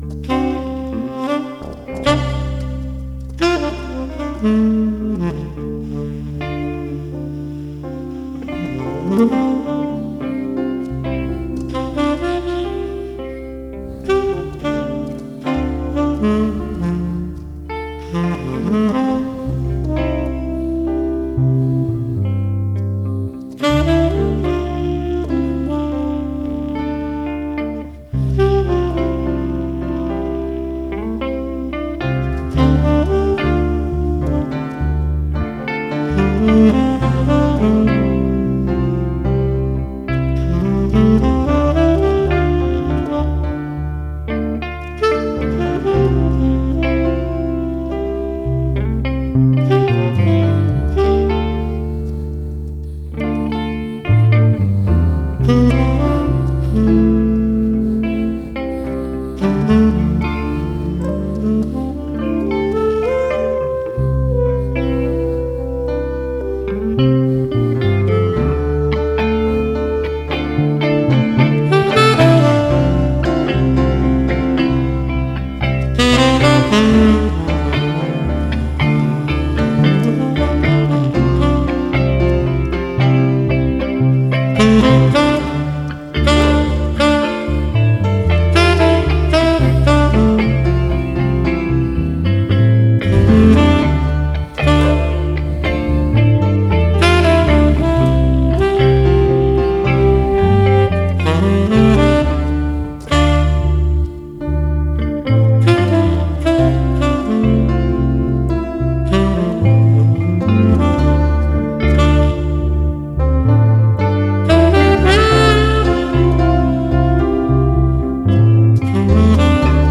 Jazz - Organ with Sax